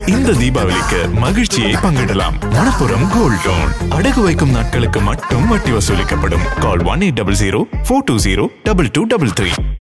Male
Indian Accent /Neutral English voice with a warm, clear, and versatile tone.
Radio Commercials